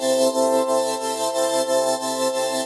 SaS_MovingPad02_90-C.wav